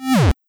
MagnetPush.wav